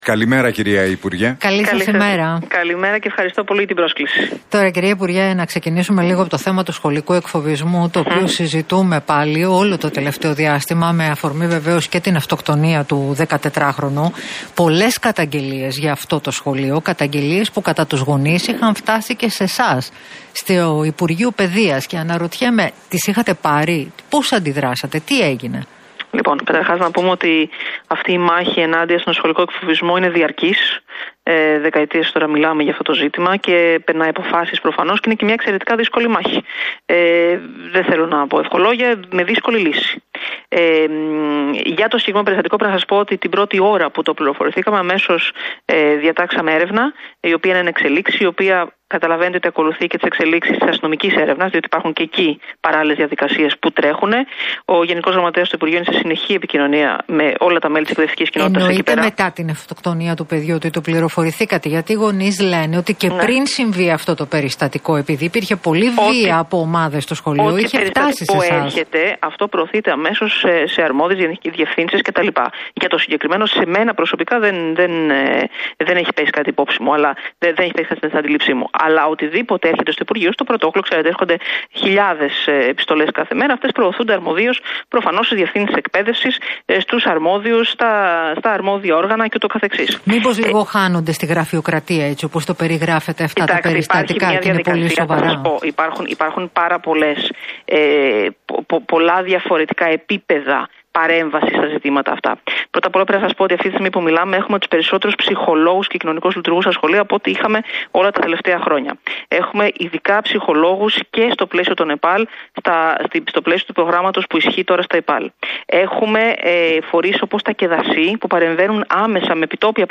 Κεραμέως στον Realfm 97,8: Ετοιμάζουμε μια οριζόντια κυβερνητική παρέμβαση για τον σχολικό εκφοβισμό